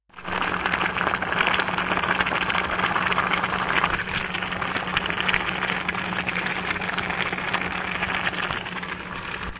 沸腾的泡沫 " 沸腾的泡沫 4 清洗
描述：一个锅里煮着意大利面条的小录音。 这个版本使用了Wah和Phaser效果，使其听起来类似于洗衣机。
Tag: 酿造 气泡